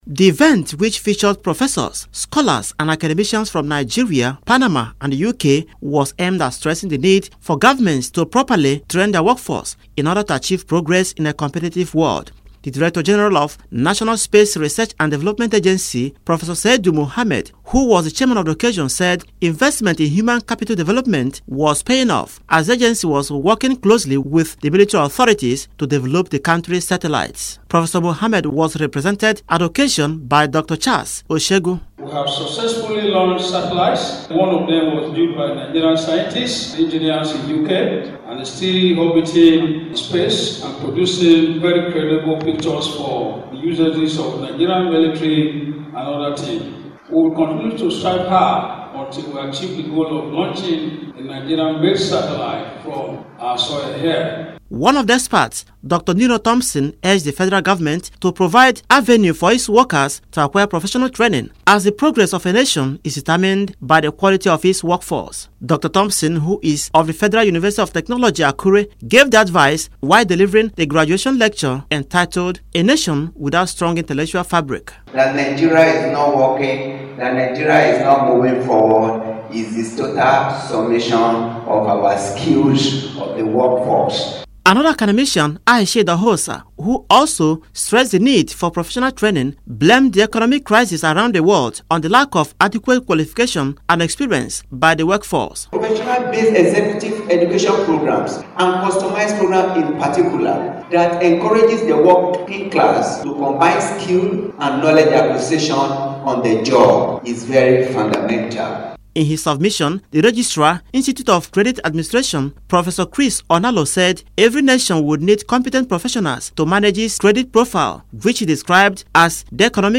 The Director General, National Space Research and Development Agency, Profesor Seidu Mohammed, says the launching of a Nigerian-made satellite will be achieved much sooner if all the relevant stakeholders collaborate effectively. Profesor Mohammed made the remark in a message to the 4th Graduation Ceremony of the Post Graduate School of Credit and Financial Management in Lagos.